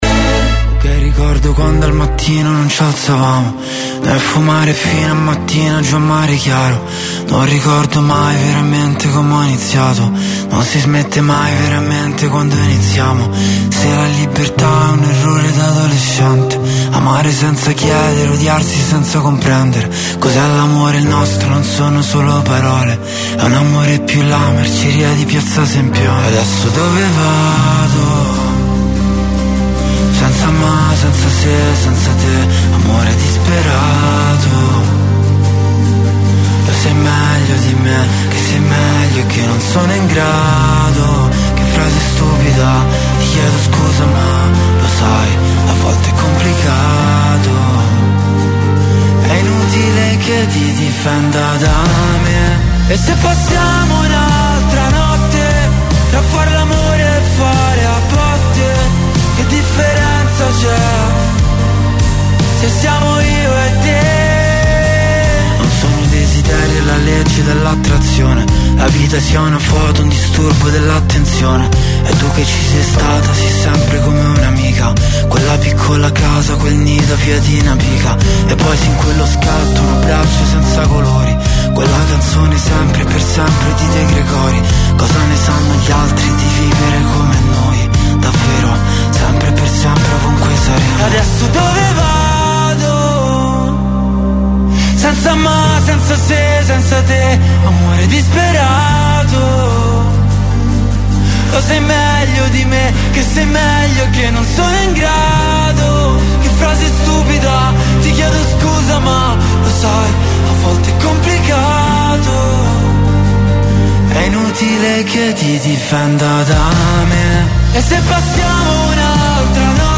COLLEGAMENTI IN DIRETTA CON I CANALI TV ALL NEWS, OSPITI AL TELEFONO DAL MONDO DELLO SPETTACOLO, DELLA MUSICA, DELLA CULTURA, DELL’ARTE, DELL’INFORMAZIONE, DELLA MEDICINA, DELLO SPORT E DEL FITNESS.